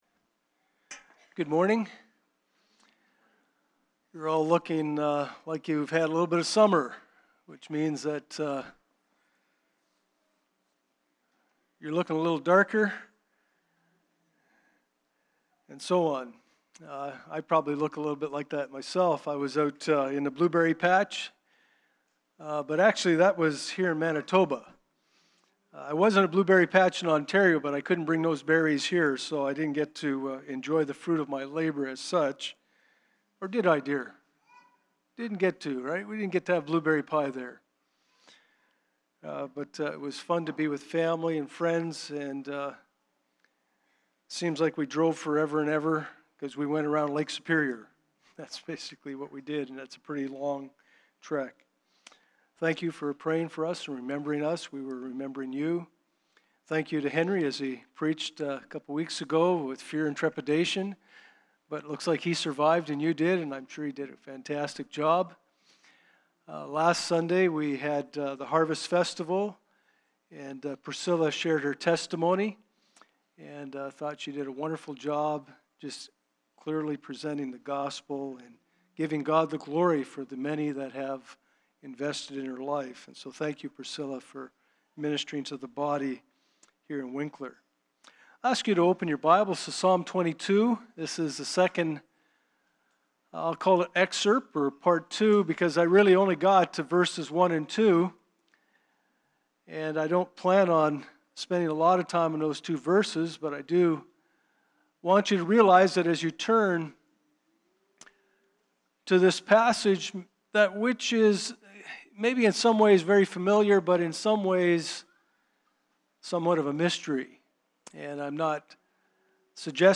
Hebrews 5:1-11 Service Type: Sunday Morning « Jesus